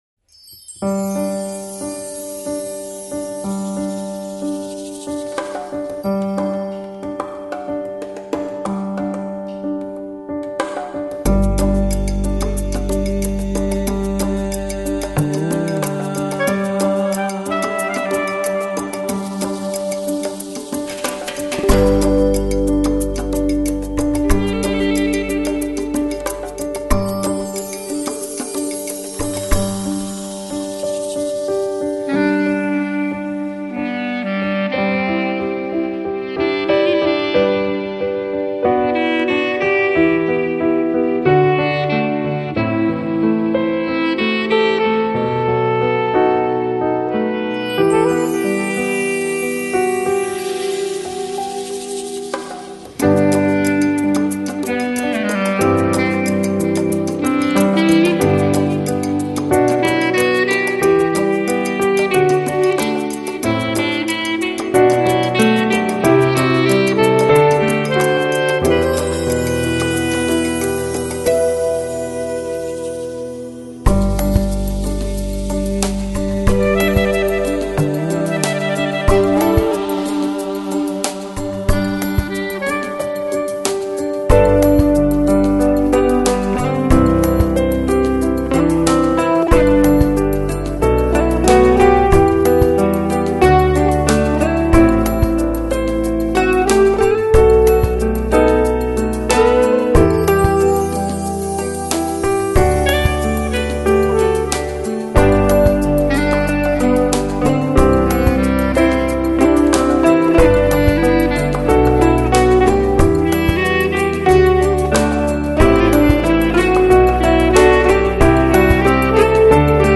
Electronic, Lounge, Chill Out, Downtempo Год издания